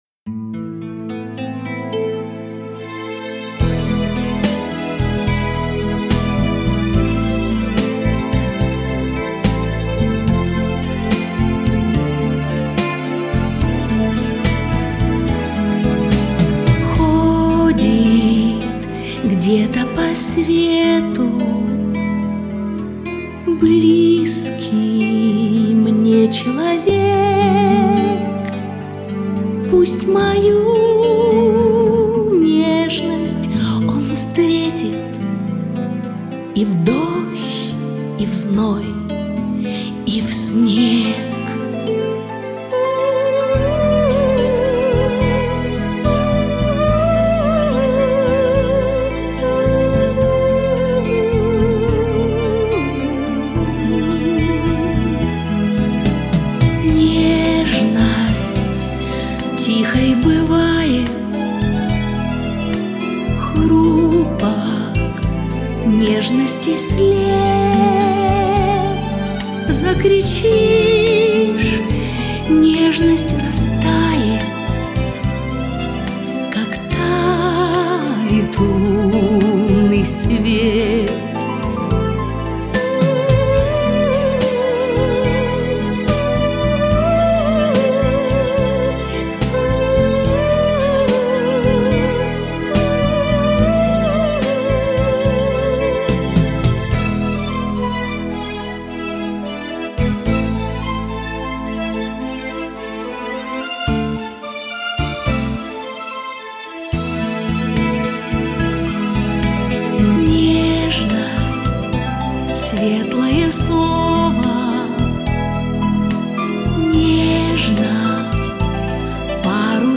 Качество существенно лучше.